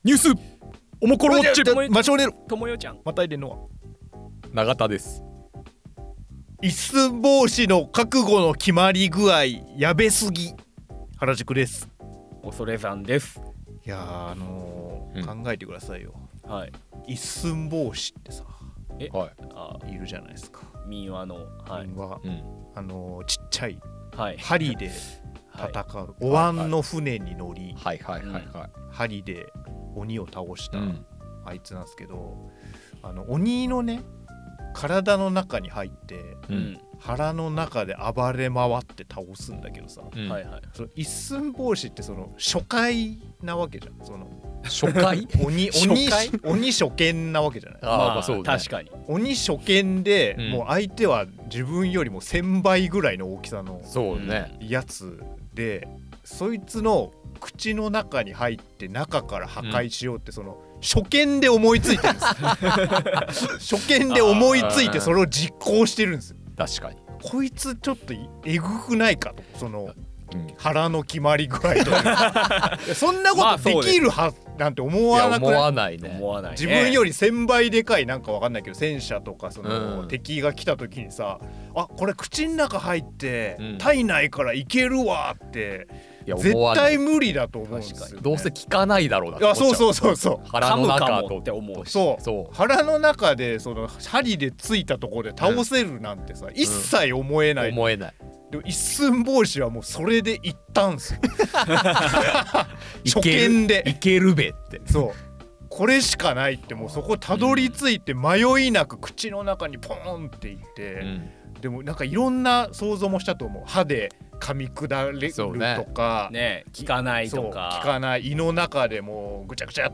オモコロ編集部の3人が気になるニュースについて語ります。